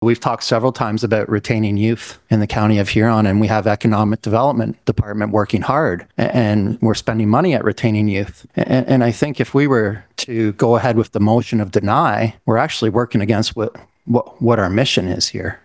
Mayor of Goderich Trevor Bazinet was insistent that if council denied the application, it could send the message that they were pushing young, working people out of the county.